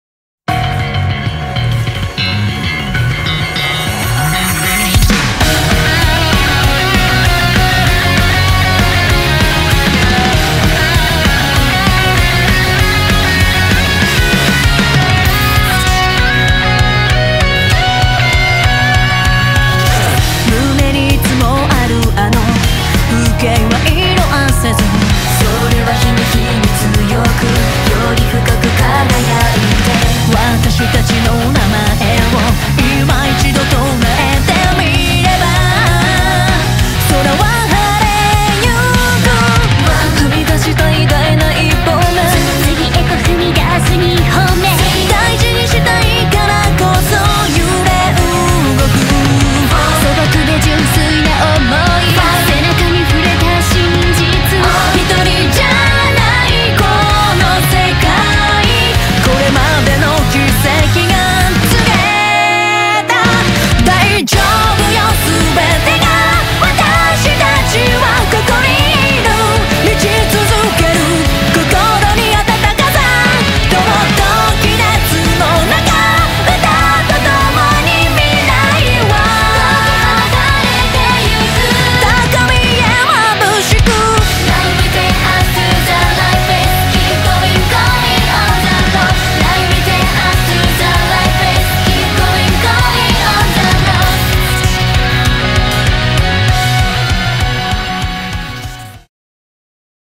BPM195
Audio QualityCut From Video